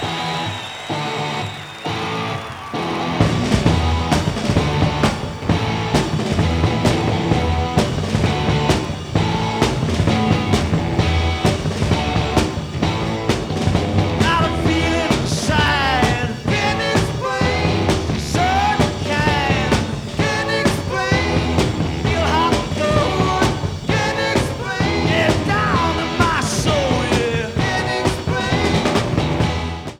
The mix was absolutely horrific.
Unnecessary distortion, etc...
Sound Samples (All Tracks In Stereo)